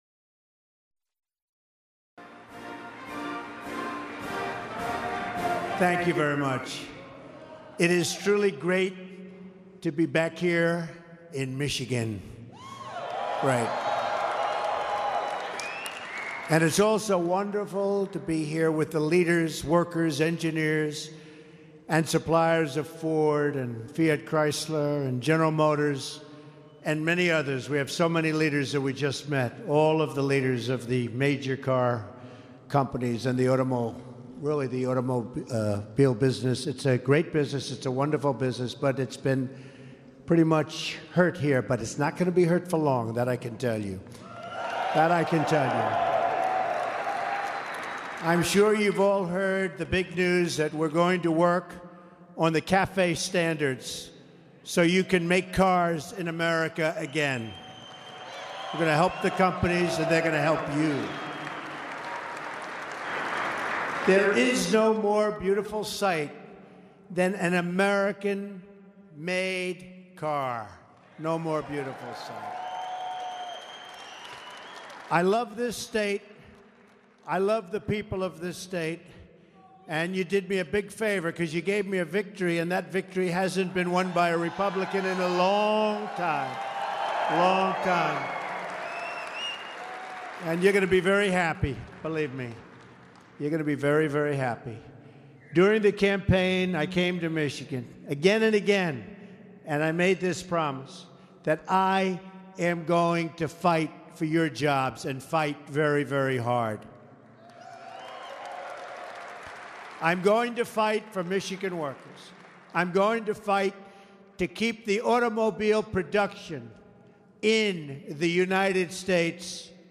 U.S. President Donald Trump speaks at the American Center for Mobility